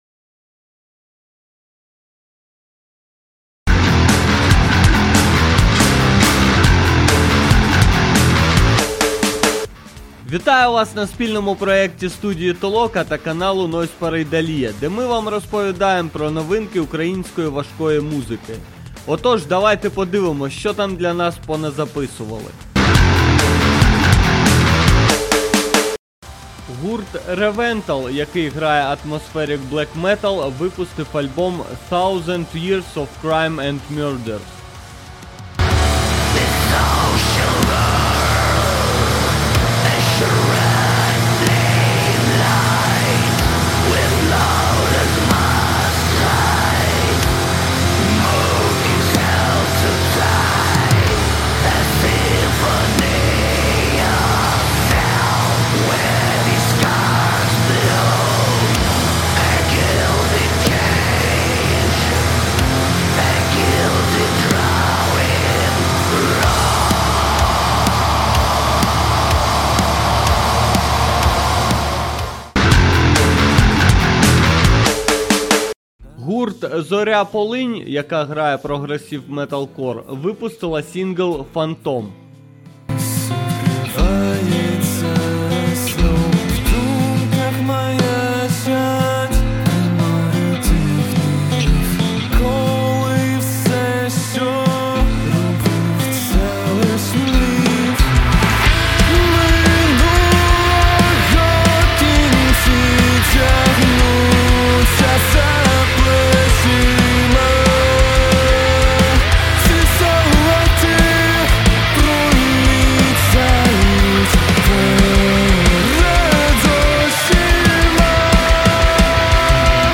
Стиль: Подкаст